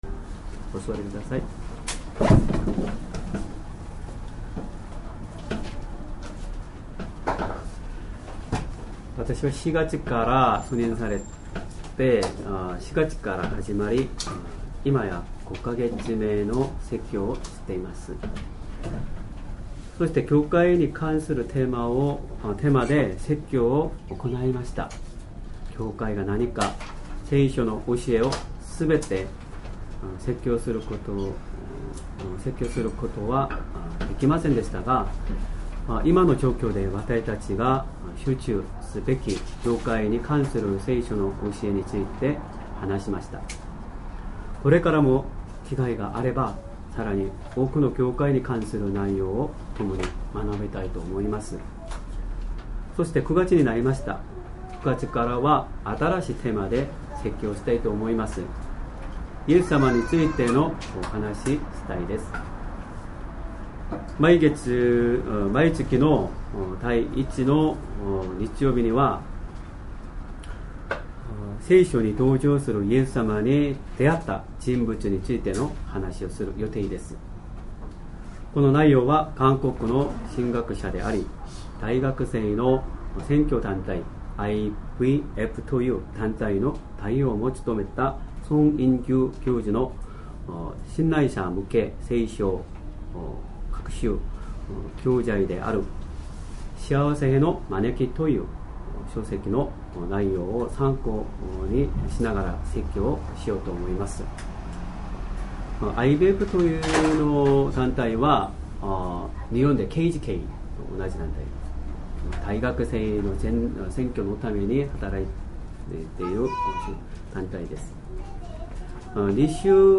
Sermon
2023年 9月3日 主日礼拝 説教 イエス様に会うペテロ ルカの福音書 ５：1～11 5:1 さて、群衆が神のことばを聞こうとしてイエスに押し迫って来たとき、イエスはゲネサレ湖の岸辺に立って、 5:2 岸辺に小舟が二艘あるのをご覧になった。